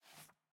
sfx_ui_map_panel_appear.ogg